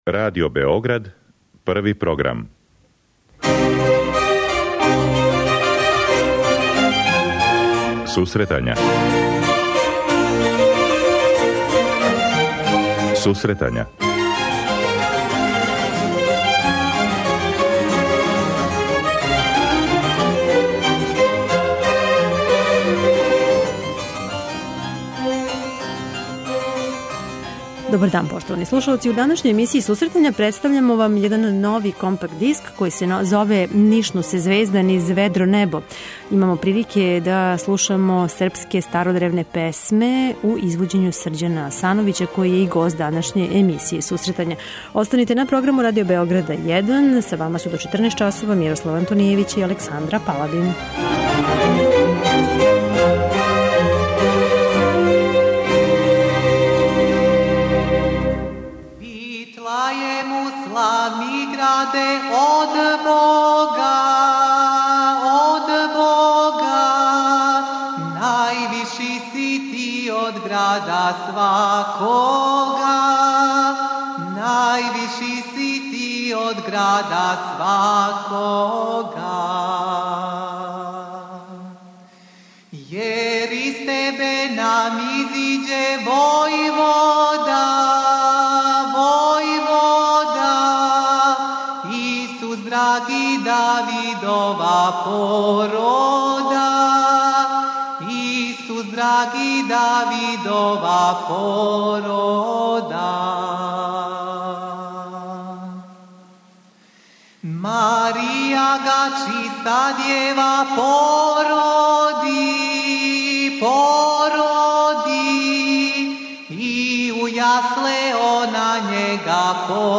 Све песме његовог репертоара изводе се без инструменталне пратње како би била очувана форма у којој су традиционално певане.